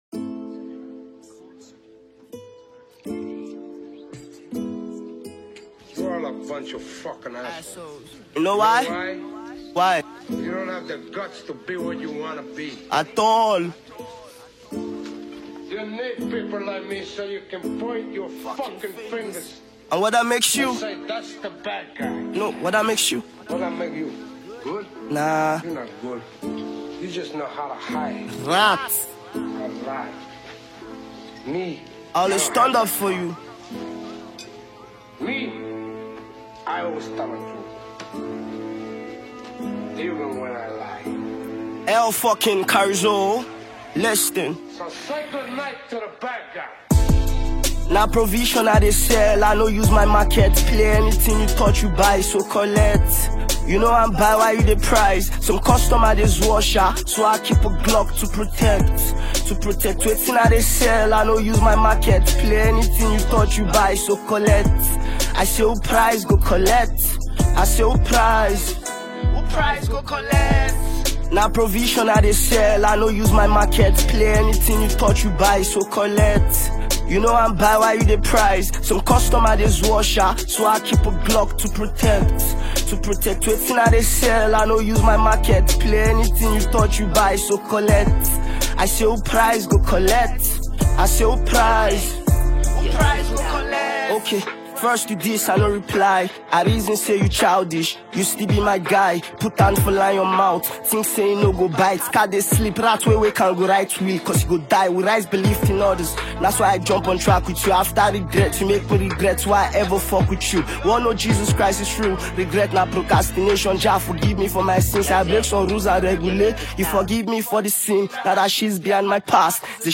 Profoundly gifted Nigerian artist, rapper, and tune arranger